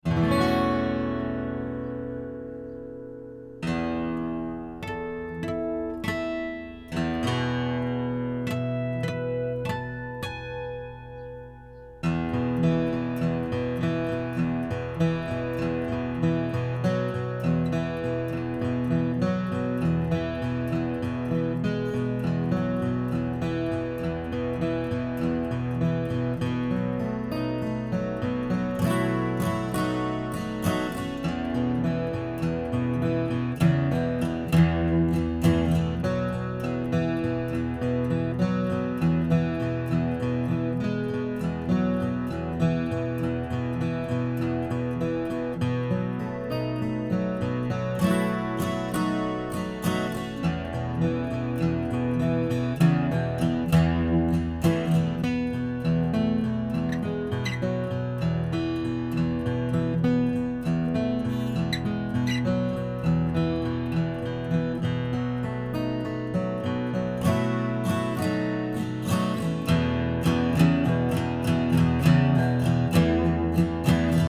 Acoustic tone?
I didn't think the low end issue was coming from the room. It sounded like it was coming from the instrument.
I'm having a problem with pick noises and squeaks.